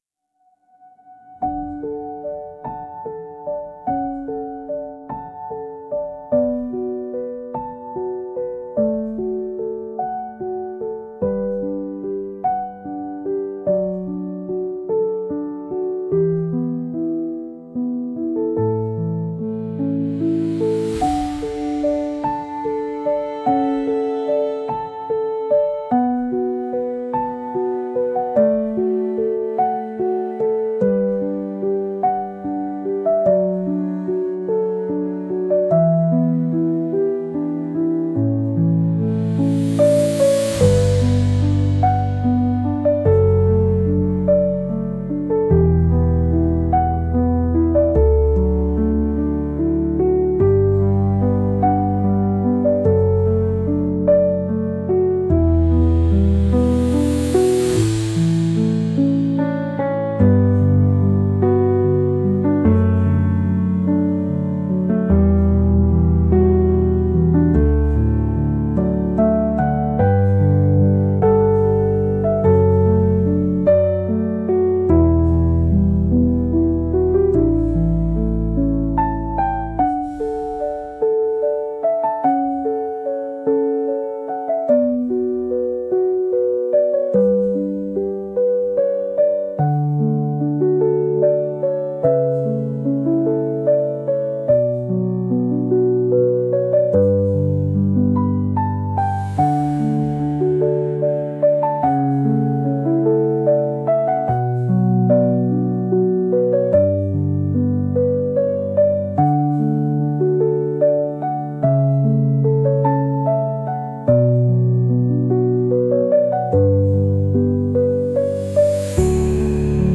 분위기 감성적인
BPM 80
유튜브배경음악